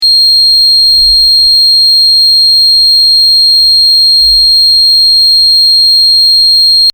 High Tone-
3500 Hz
high_tone.mp3